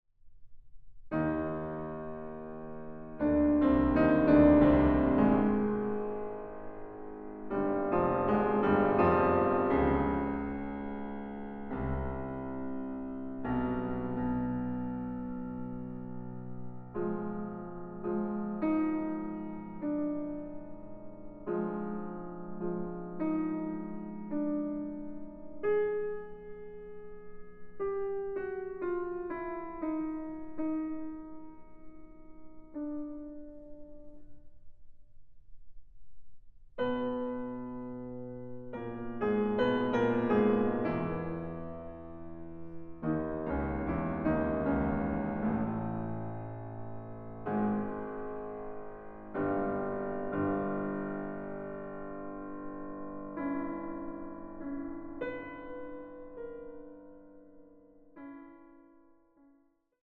Klavier
Aufnahme: Mendelssohn-Saal, Gewandhaus Leipzig, 2024
ausgeführt im monumentalen Klang zweier Konzertflügel.
eine klangvolle und farbige Fassung für zwei Klaviere